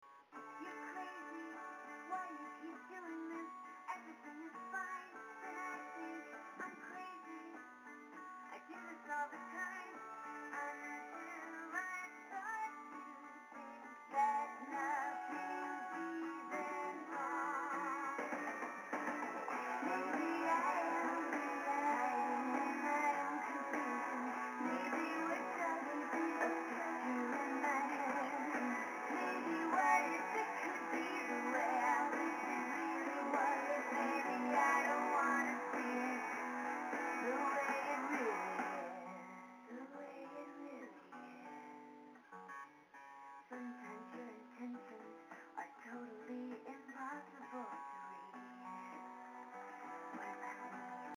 電話でブログ投稿〜BLOGROWN: